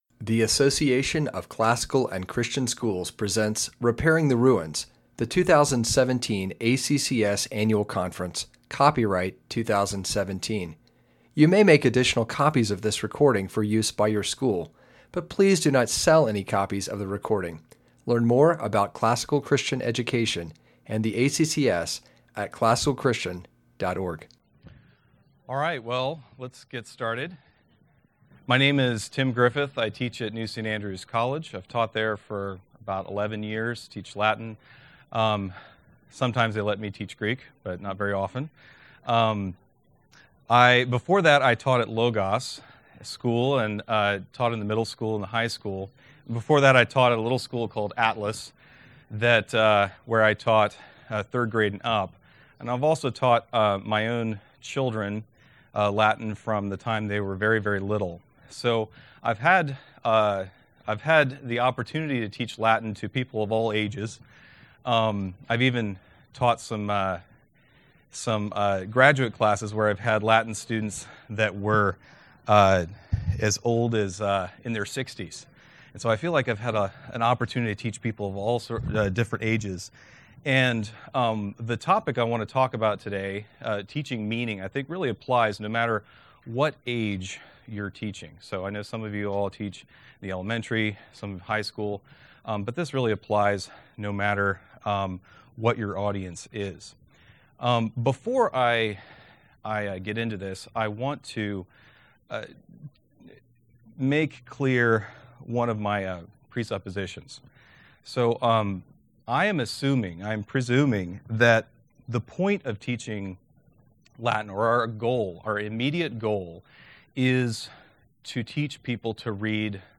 2017 Workshop Talk | 0:50:48 | All Grade Levels, Latin, Greek & Language